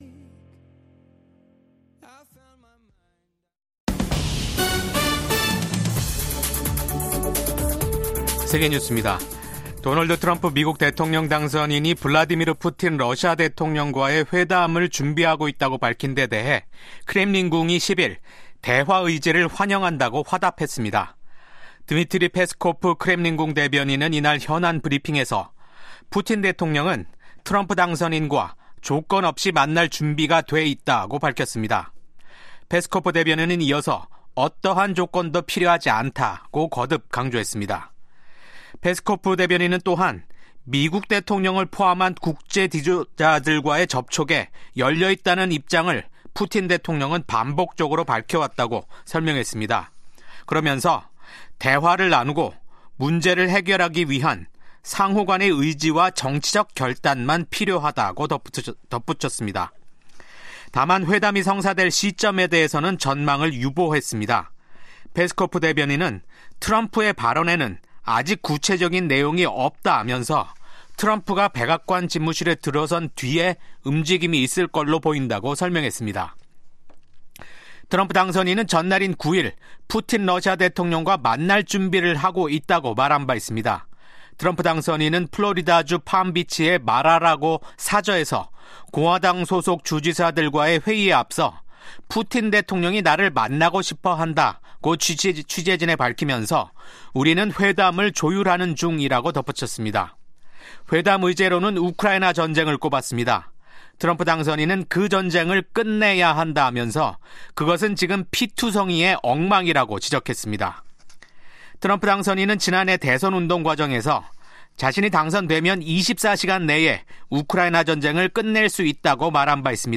VOA 한국어 아침 뉴스 프로그램 '워싱턴 뉴스 광장'입니다. 미국 제 39대 대통령을 역임한 지미 카터 전 대통령의 장례식이 9일 워싱턴 DC에서 국장으로 엄수됐습니다. 100세를 일기로 별세한 지미 카터 전 미국 대통령의 한반도 관련 유산은 상반된 평가를 받고 있습니다. 우크라이나 전쟁에 파견된 북한군 사상자가 속출하고 있다는 소식이 전해지고 있는 가운데 북한은 여전히 파병 사실 자체를 확인도 부인도 하지 않고 있습니다.